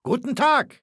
Datei:Maleold01 ms06 hello 000284e8.ogg
Fallout 3: Audiodialoge